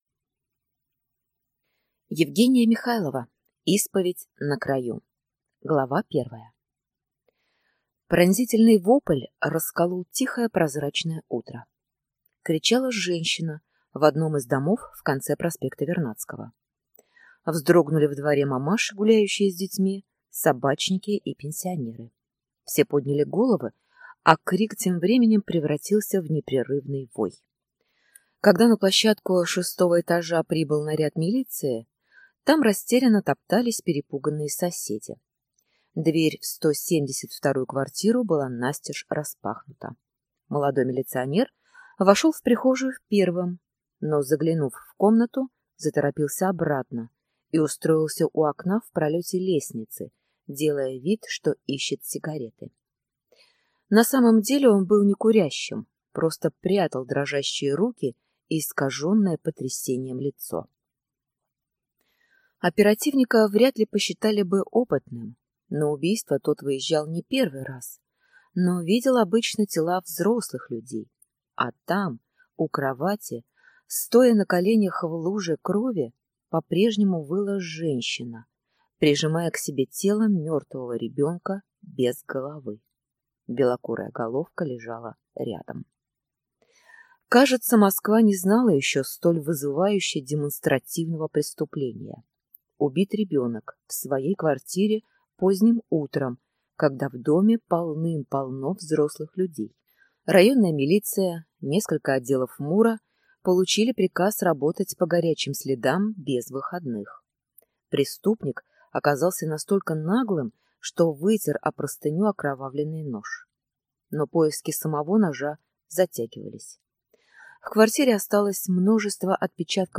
Аудиокнига Исповедь на краю | Библиотека аудиокниг